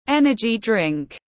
Nước tăng lực tiếng anh gọi là energy drink, phiên âm tiếng anh đọc là /ˈen.ə.dʒi ˌdrɪŋk/
Energy drink /ˈen.ə.dʒi ˌdrɪŋk/